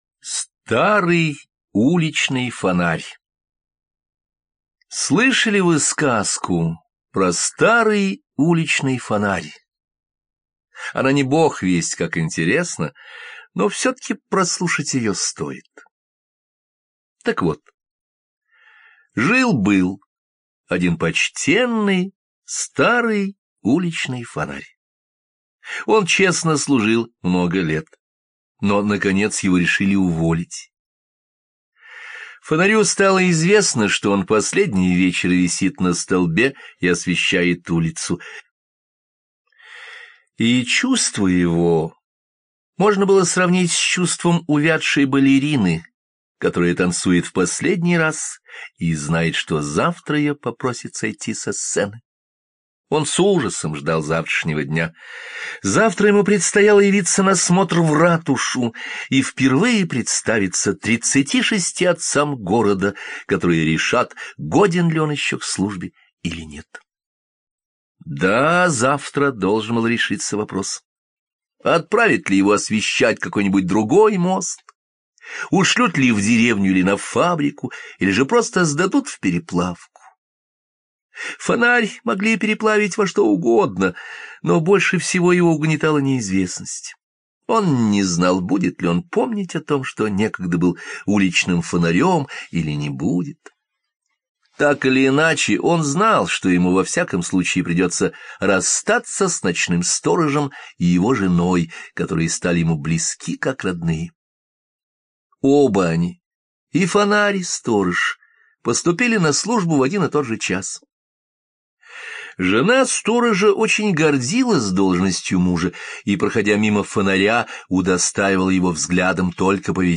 Старый уличный фонарь — аудиокнига Андерсена, которую вы можете слушать онлайн или скачать.